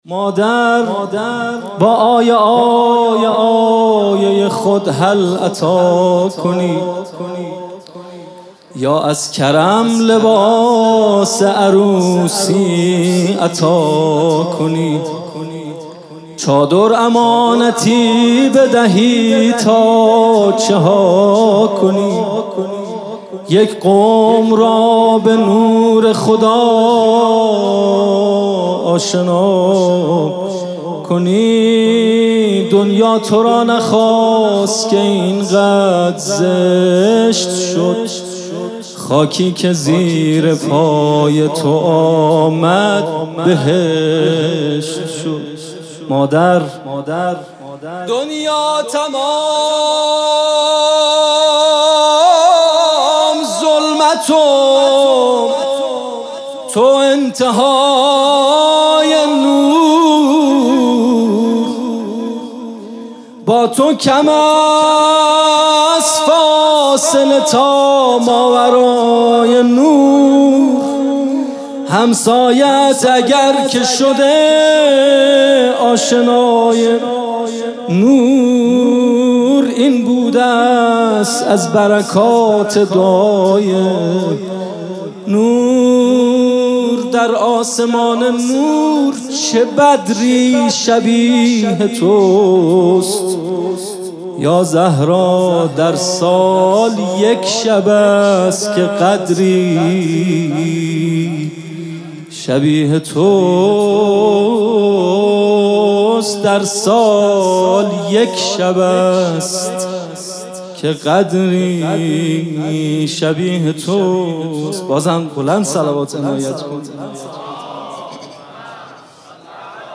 music-icon مدح